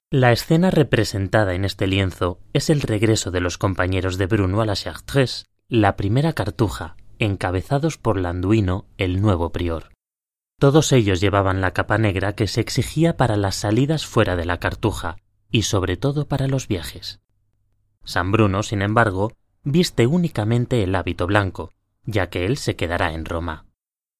I'm a voice over artist with home studio.
I have a dinamic and fresh young-adult male voice and I guarantee that the assignments will allways be finished on time.
kastilisch
Sprechprobe: Sonstiges (Muttersprache):